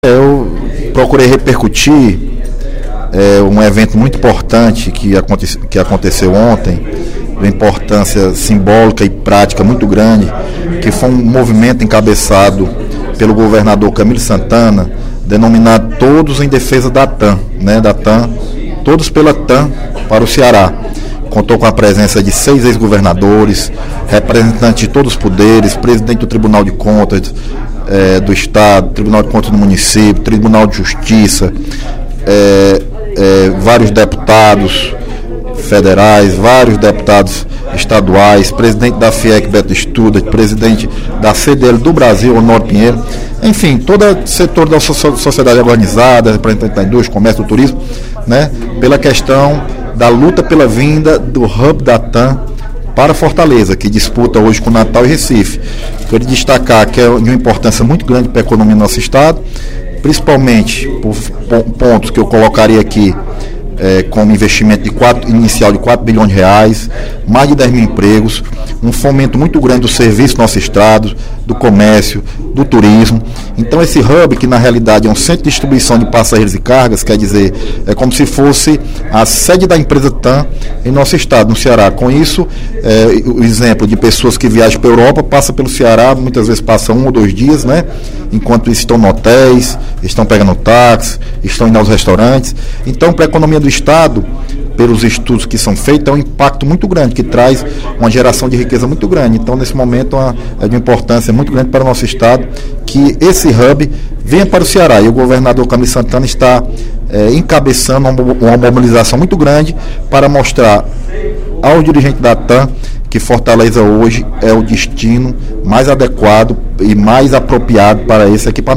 O vice-líder do Governo na Assembleia, deputado Leonardo Pinheiro (PSD), falou sobre o ato de mobilização Todos unidos pelo HUB da TAM no Ceará, durante o pronunciamento no primeiro expediente da sessão plenária desta terça-feira (23/06).